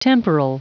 Prononciation du mot temporal en anglais (fichier audio)
Prononciation du mot : temporal